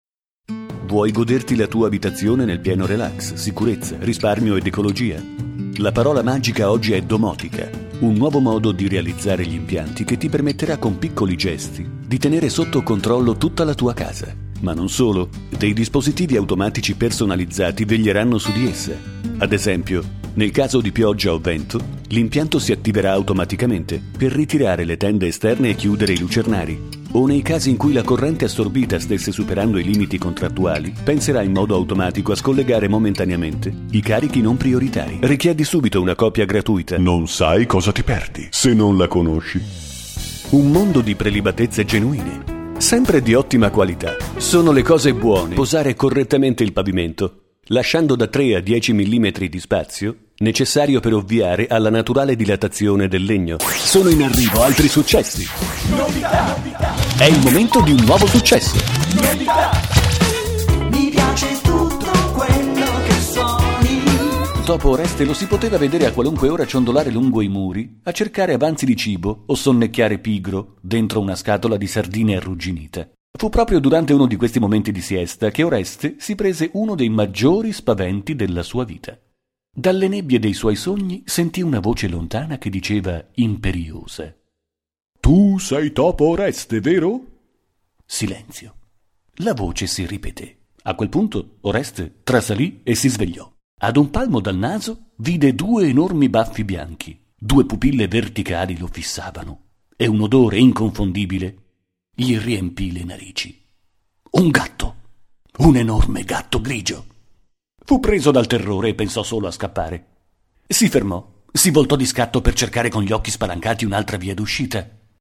Kein Dialekt
Sprechprobe: Werbung (Muttersprache):
Speaker radio-tv-multimedia Soundesign - editing audio